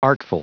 Prononciation du mot artful en anglais (fichier audio)
Prononciation du mot : artful